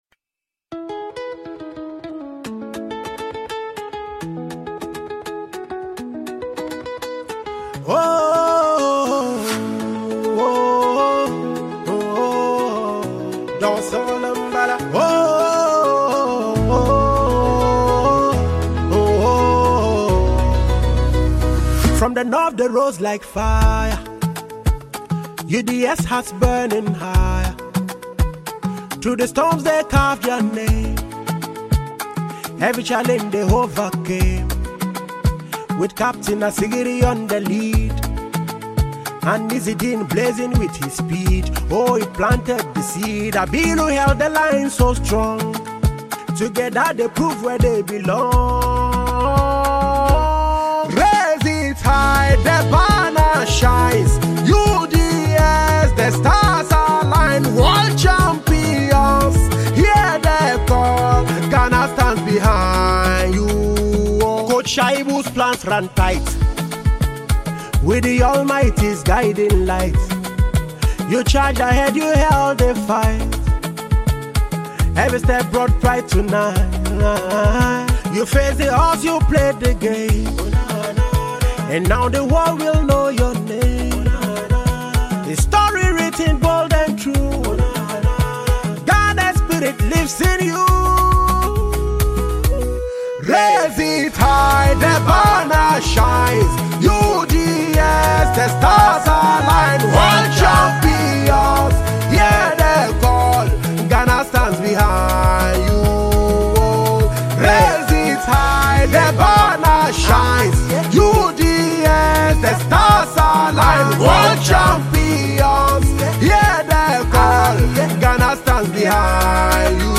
GHANA MUSIC
Northern based Ghanaian talented singer and songwriter